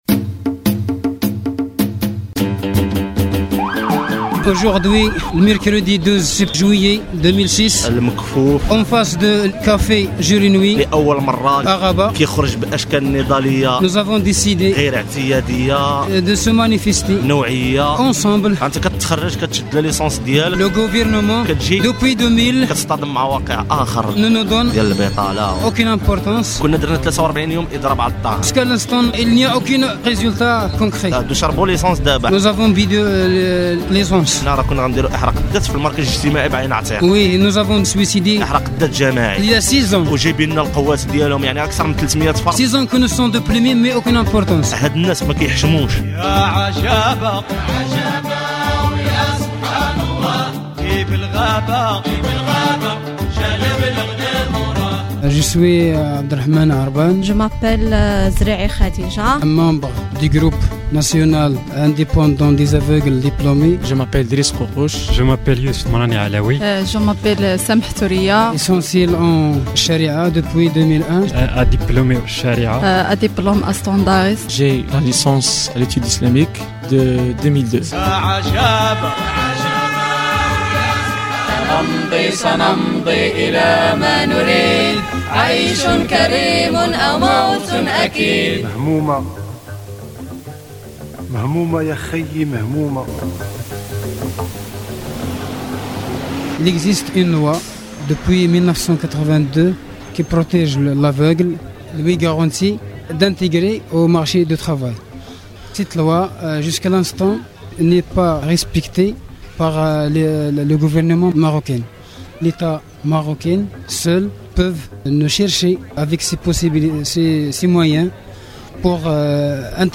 Aveugles marocains cherchent emploi Un reportage